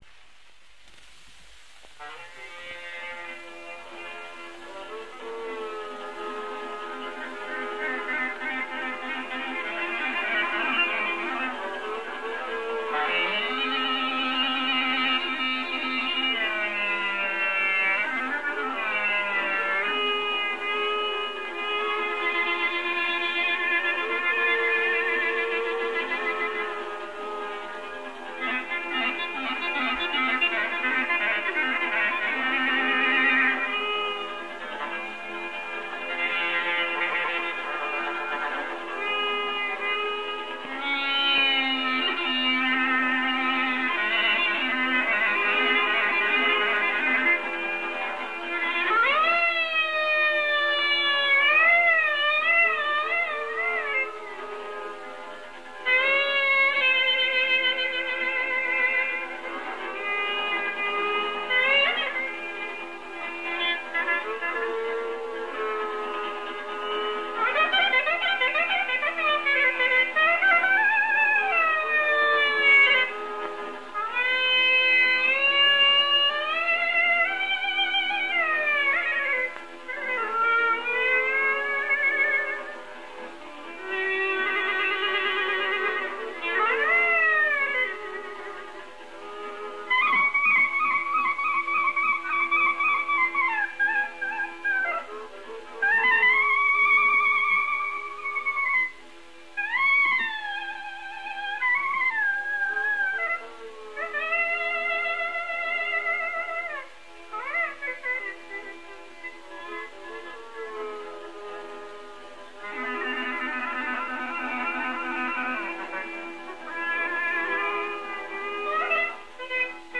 Mjaft qartë konstatojmë se gërneta duket sikur këndon, aq e ngjashme është me këngëtarin në mënyrën e artikulimit të tingullit.
Pjesa e dytë, vallja, është një muzikë e gëzuar në metër dypjesëtues, tipike për vallet e vajzave dhe grave të zonës së Leskovikut dhe Përmetit.
Ndërtimi më i preferuar për sazet ka qenë 1 gërnetë, 1 qemane dhe 2 llauta.